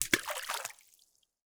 SPLASH_Small_01_mono.wav